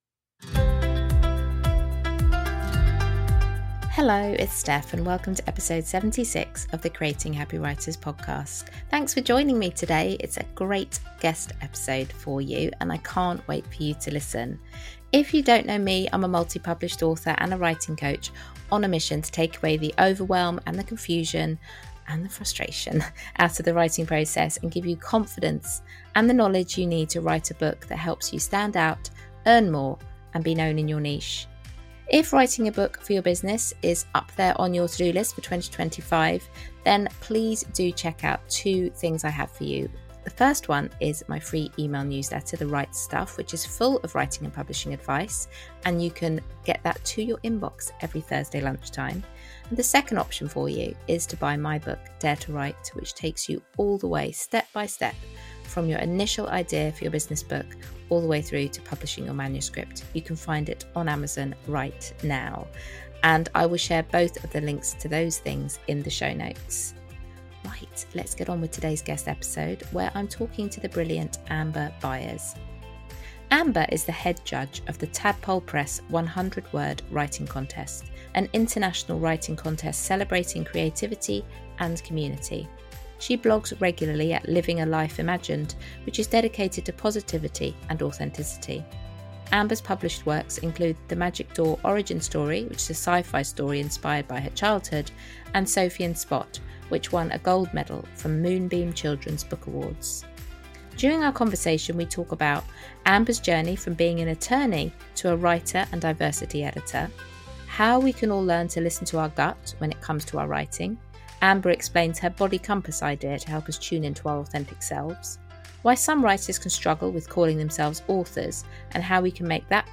During this inspiring conversation, we explore: